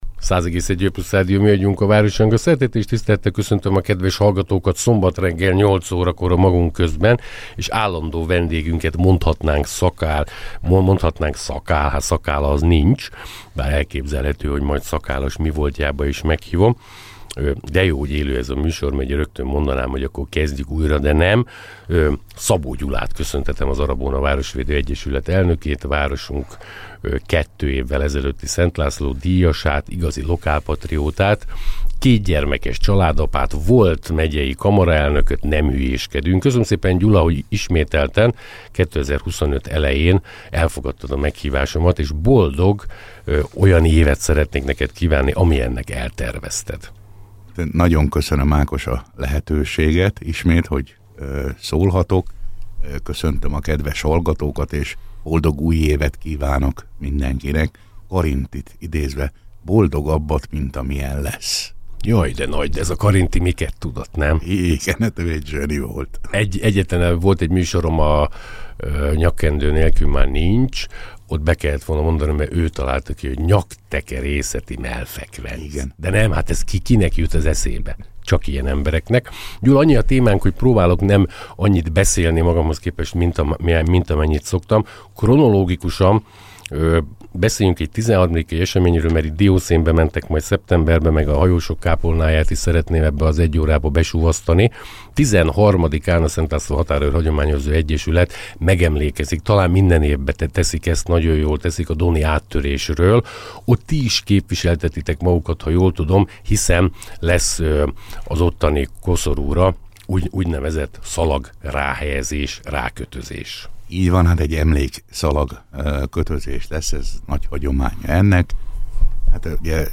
Győri beszélgetések